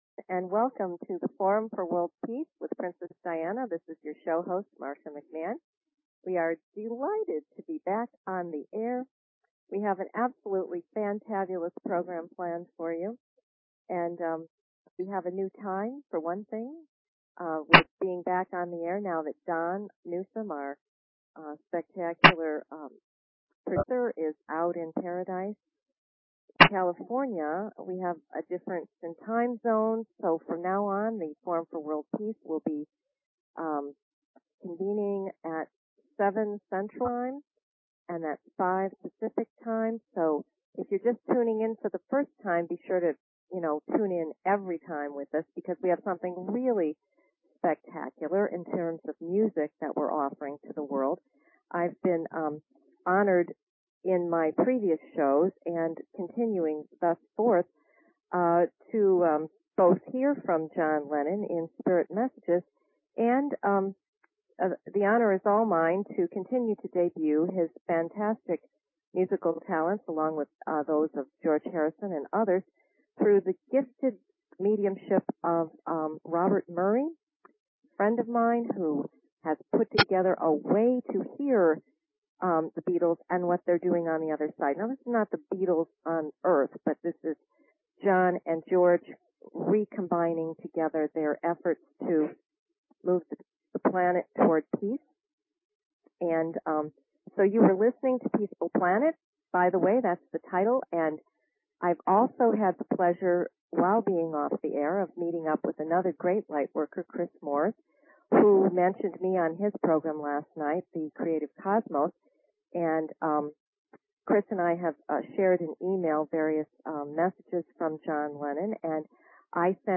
Talk Show Episode, Audio Podcast, Forum For World Peace and Courtesy of BBS Radio on , show guests , about , categorized as